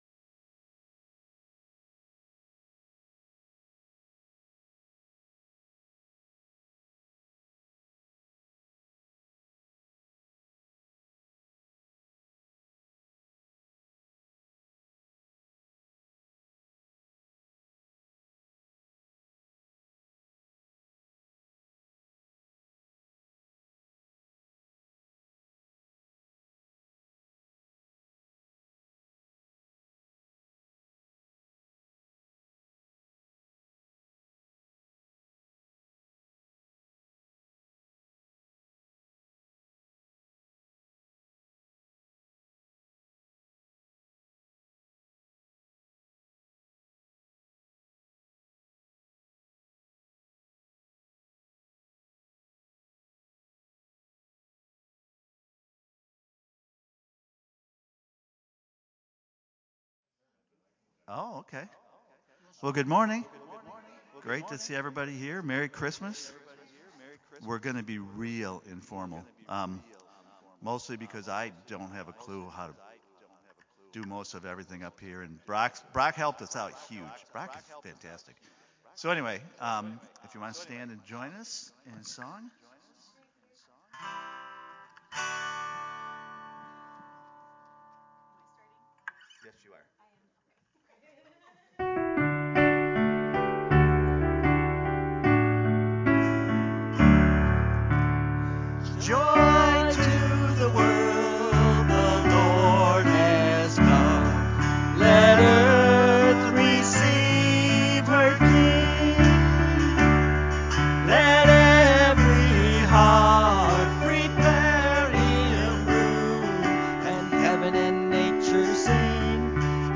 Christmas Day Worship 2022
lead us in worship on Christmas morning.